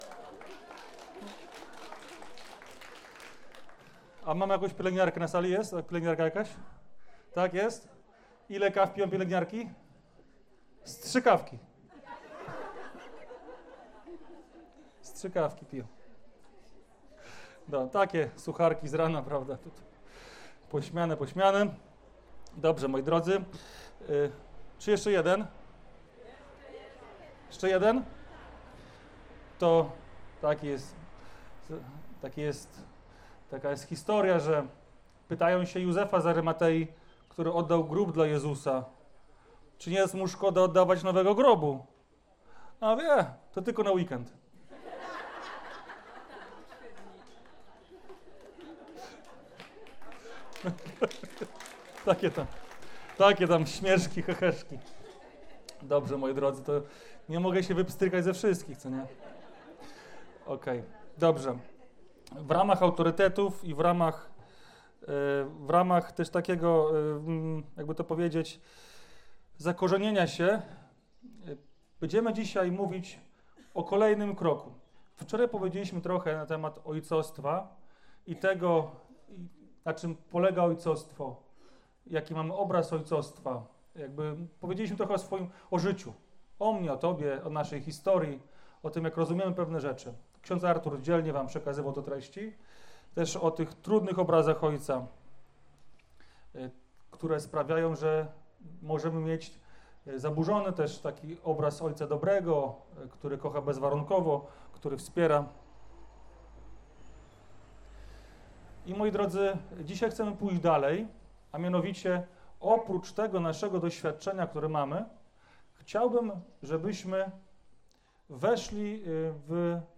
Konferencja - Jezu pokaż nam Ojca